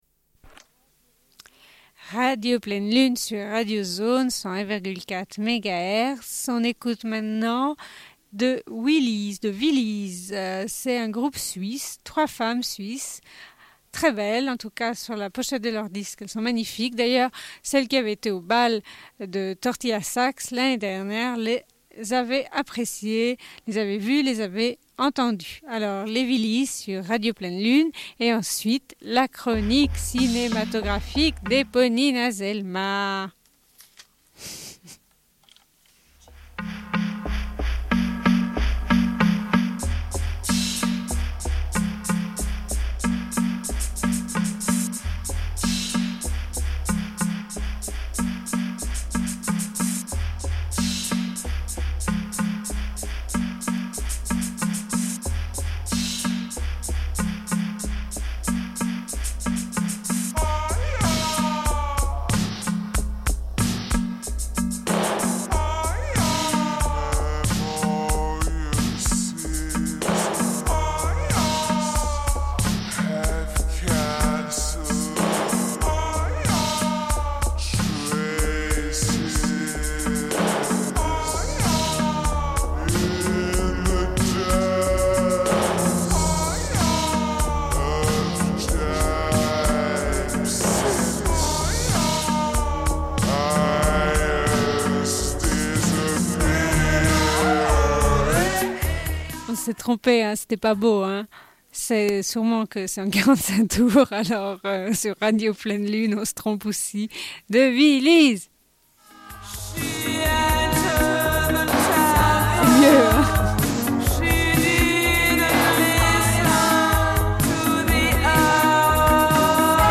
Une cassette audio, face B47:31